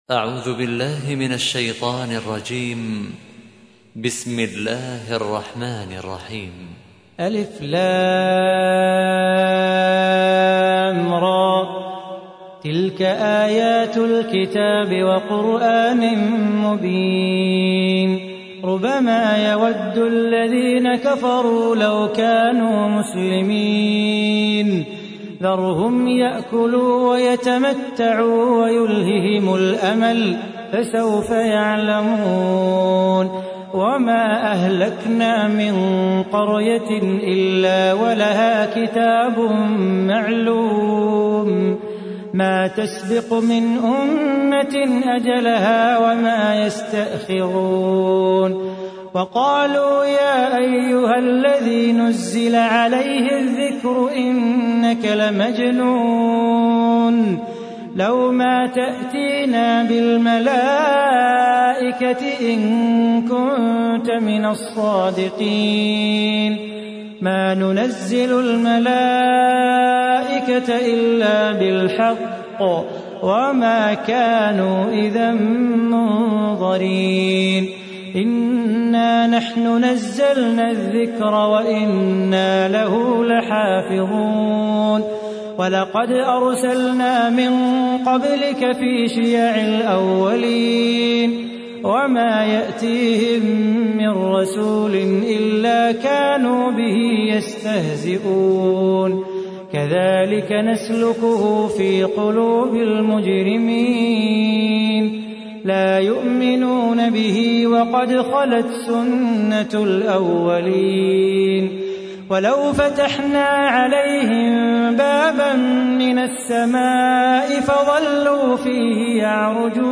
تحميل : 15. سورة الحجر / القارئ صلاح بو خاطر / القرآن الكريم / موقع يا حسين